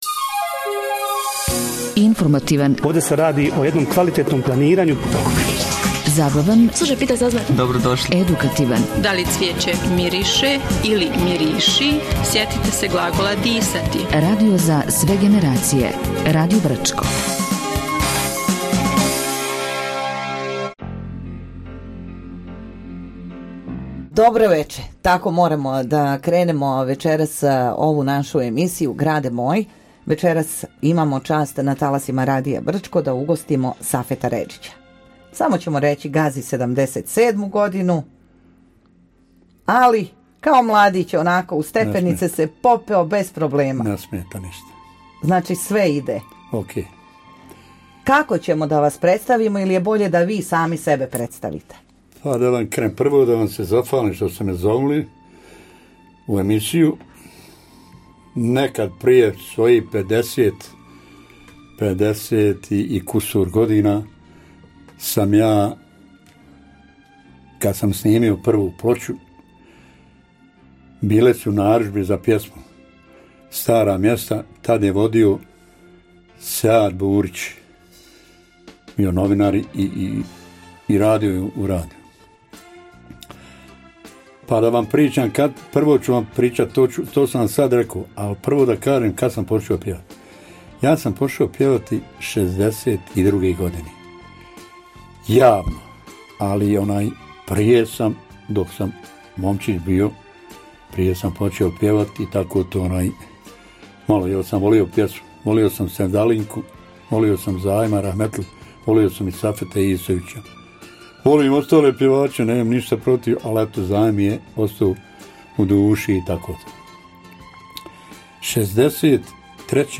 Emisija “Brčko-grade moj” – Razgovor